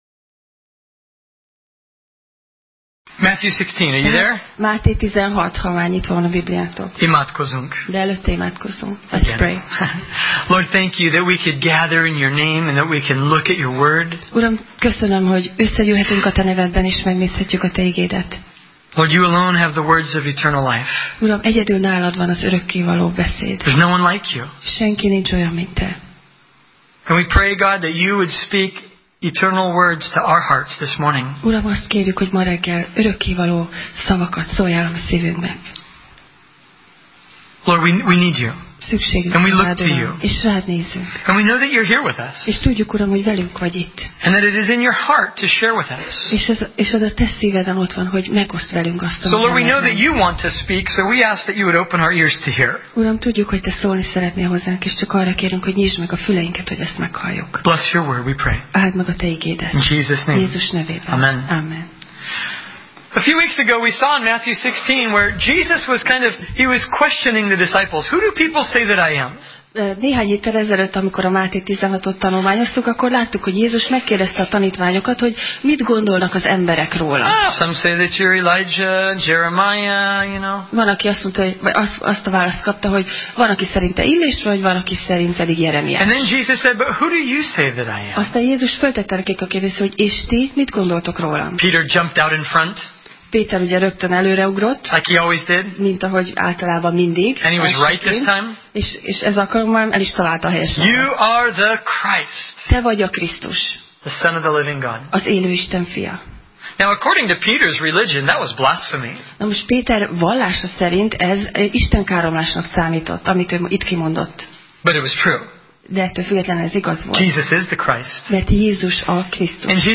Máté Passage: Máté (Matthew) 16:21-28 Alkalom: Vasárnap Reggel « Máté